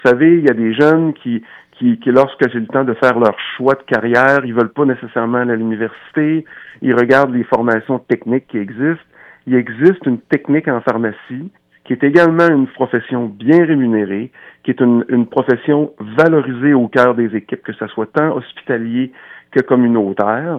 En entrevue plus tôt ce matin, celui qui pratique son métier du côté de Trois-Pistoles a tenu à souligner le caractère vocationnel de ses collègues.